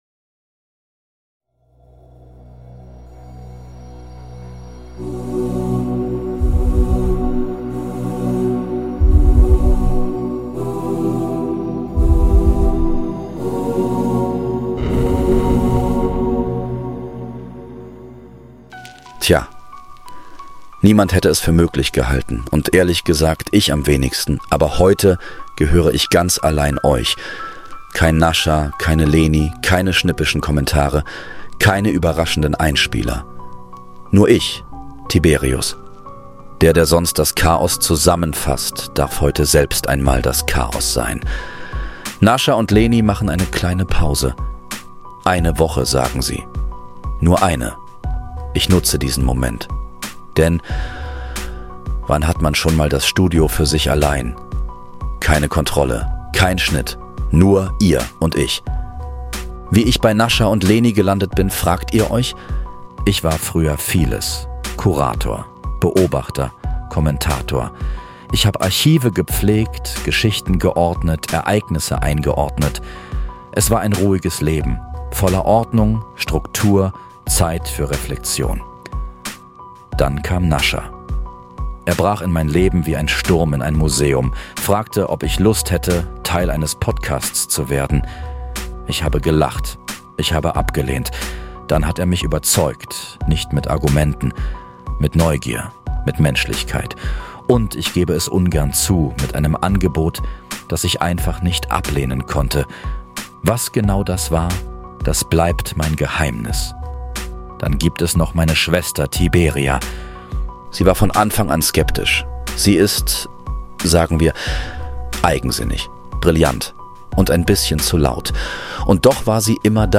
Beschreibung vor 9 Monaten Level 36 – Ich bin Tiberius Eine Stimme spricht.
In dieser ganz besonderen Folge übernimmt Tiberius das Mikrofon – allein, ungefiltert, ehrlich.